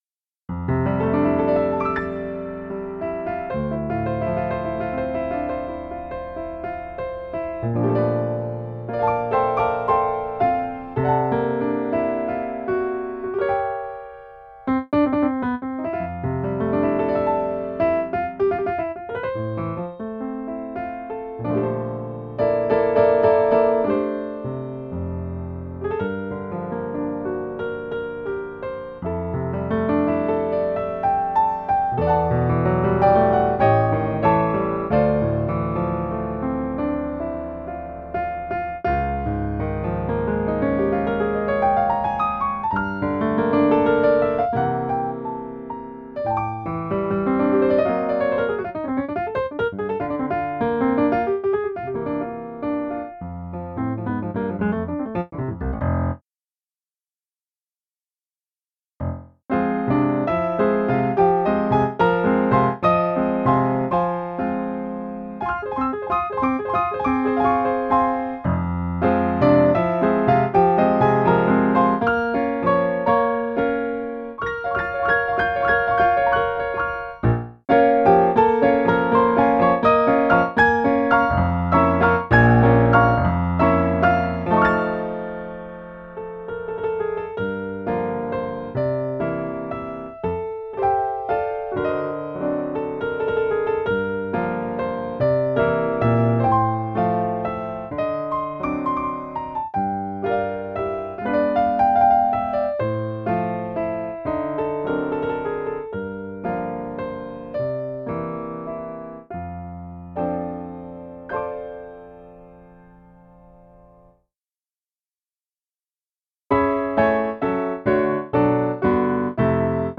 lab Synthogy Ivory Grand Pianos
fv-PIANO_X.mp3